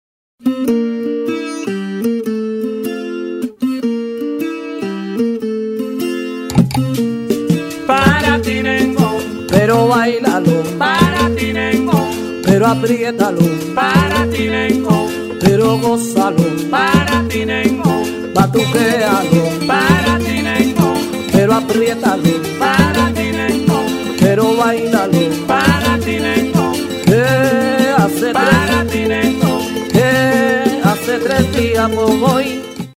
L'instrument central est le tres, guitare à 3 cordes doubles, qui était au début accompagné par les instruments les plus rudimentaires et improvisés comme des cuillères, des caisses en bois ou un tumbandera.
Le Nengón répète le même schéma rythmique tout au long de la chanson. Le tres alterne sur 2 accords, un par mesure sur une rythmique en 2/4. Le tempo du Nengón est plutôt lent.
Souvent, se crée ensuite une alternance entre le chanteur soliste et un chœur afin d'entre-couper les différents llantos (l'estribillo).
Voici un exemple de Nengón (extrait du site